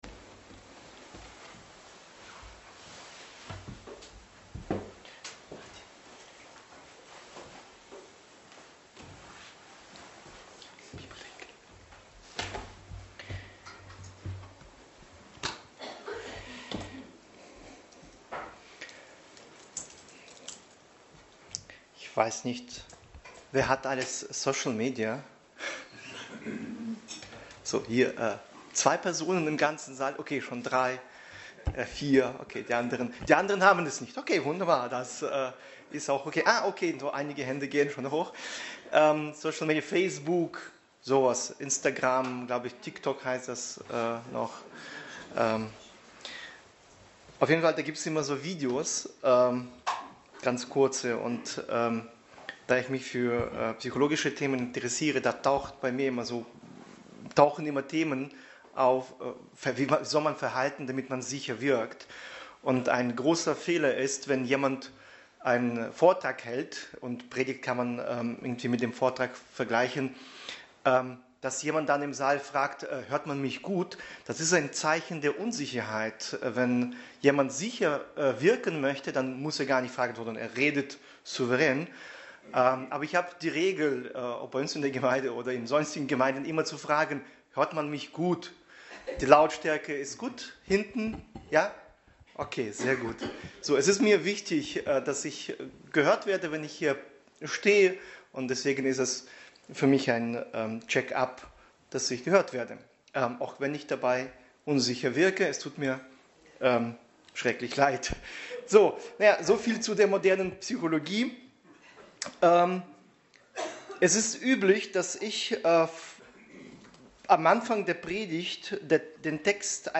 Das Heilshandeln Gottes Passage: 1. Könige 8,1-27 Dienstart: Predigt Themen: Gebet , Israel , Tempel , Treue Gottes « Wer bin ich?